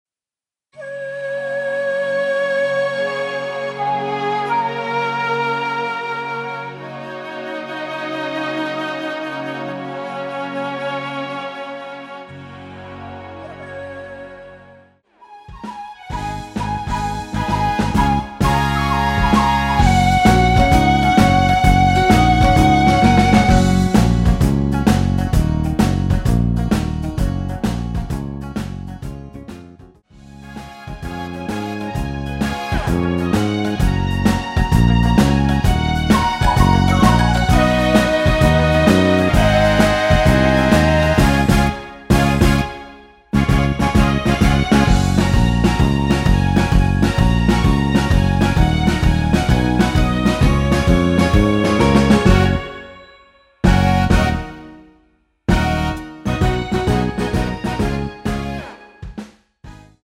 F#
앞부분30초, 뒷부분30초씩 편집해서 올려 드리고 있습니다.
중간에 음이 끈어지고 다시 나오는 이유는